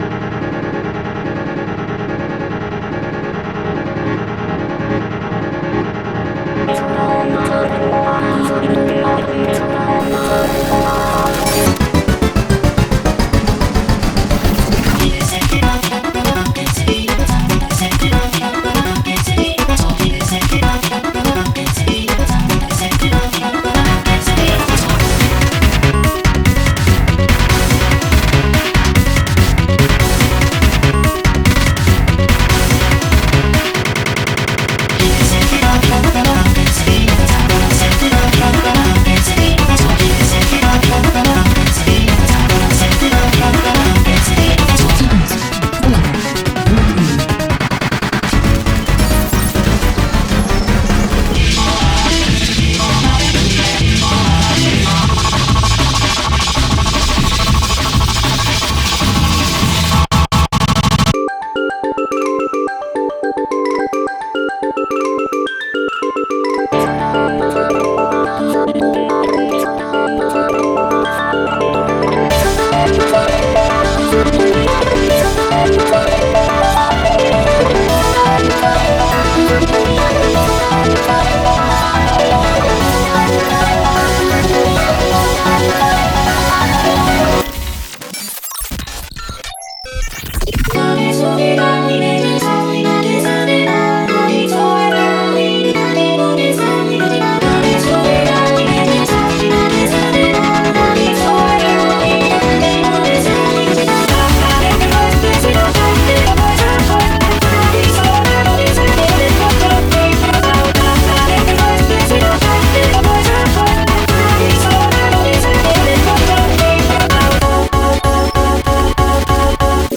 BPM135-260
Audio QualityPerfect (High Quality)
200% Vocaloid